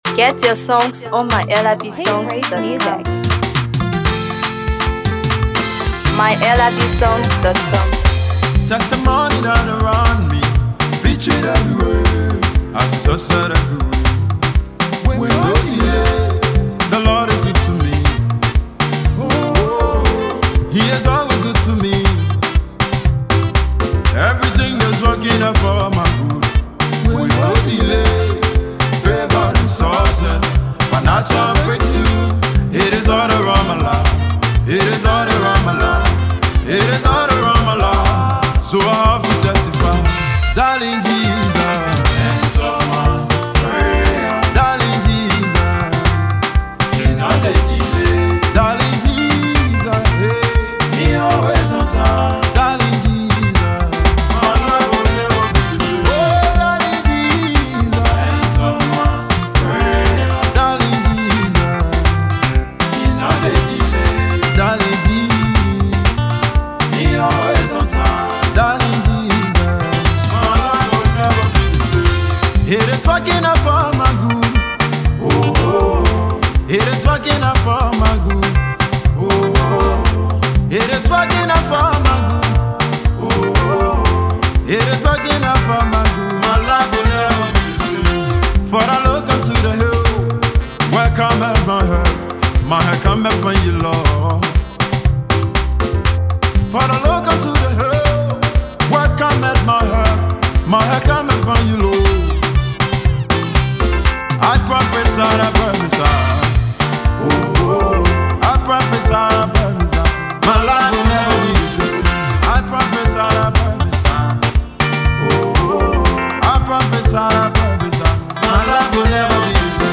Gospel
a song of deep worship and gratitude